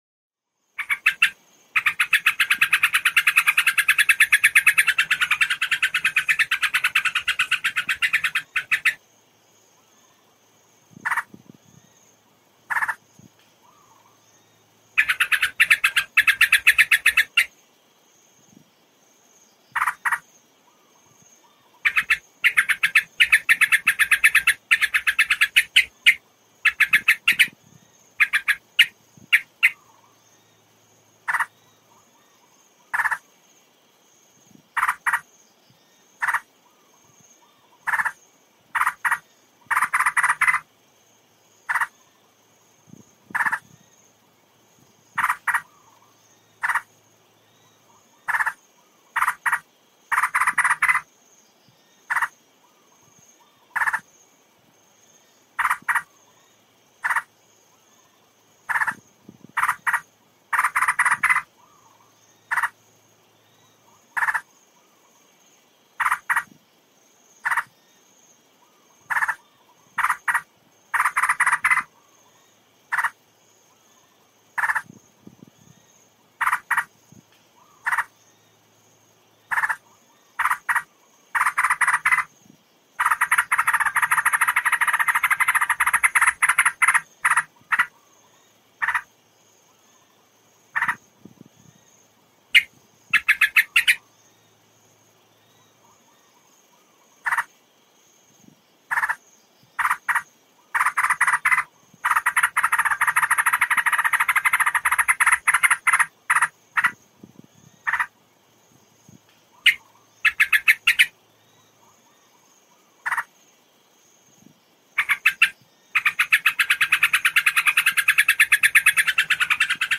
เสียงนกปรอดหน้านวล ชัด 100% mp3
หมวดหมู่: เสียงนก
มันเป็นเสียงที่ชัดเจน 100% และถ่ายจริง